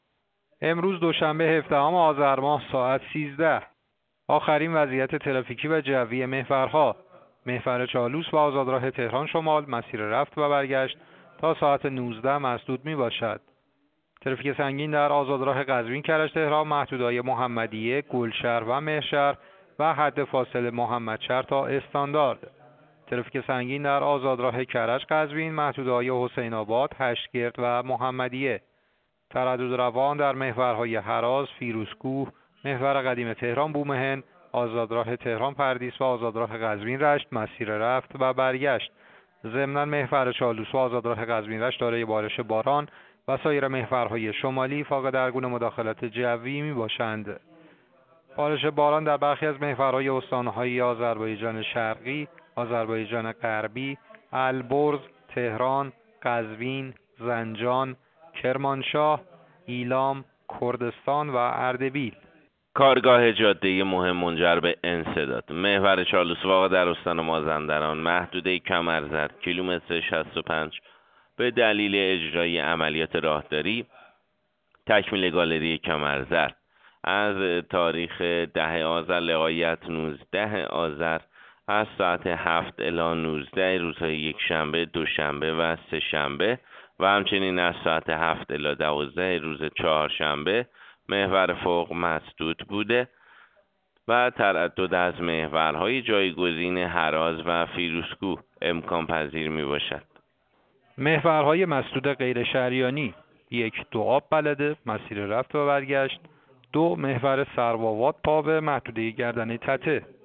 گزارش رادیو اینترنتی از آخرین وضعیت ترافیکی جاده‌ها ساعت ۱۳ هفدهم آذر؛